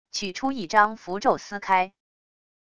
取出一张符咒撕开wav音频